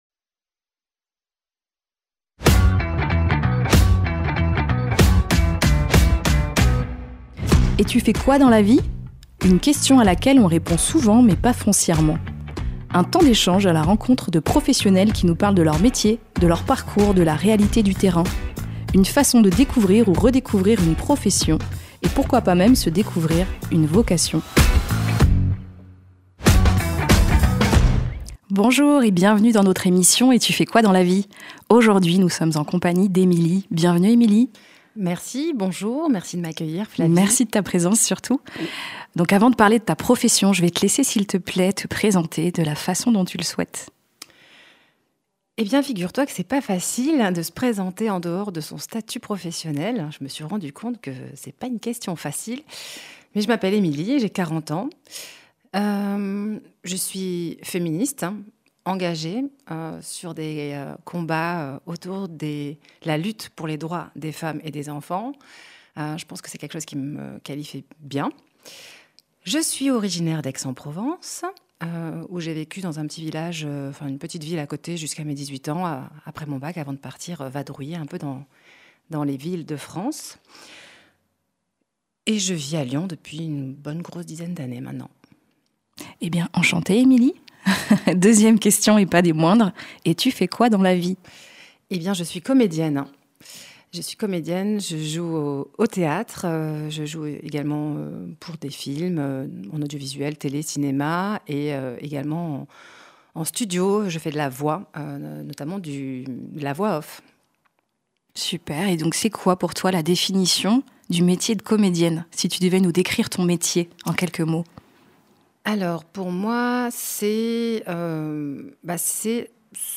Découvrez des conversations sincères avec des professionnels authentiques qui sauront, qui sait, éveiller chez vous, une ou des vocations.